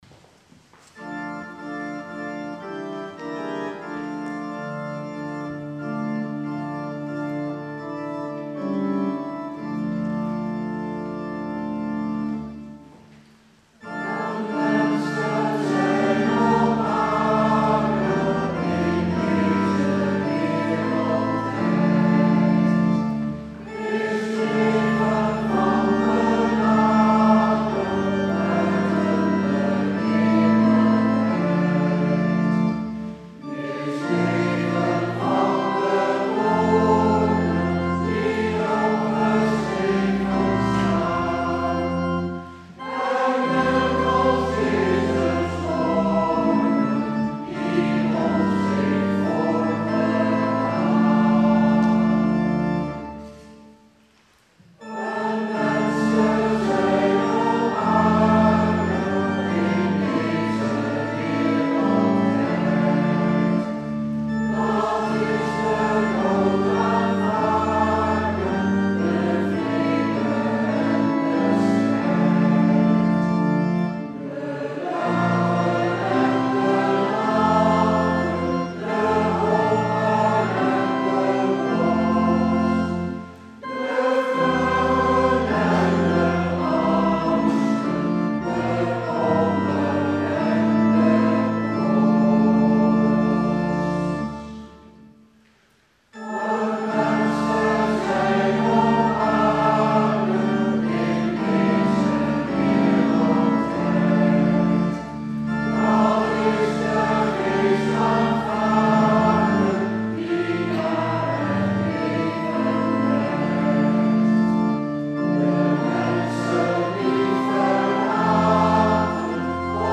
Aswoensdag, 26 februari 2020
Openingslied: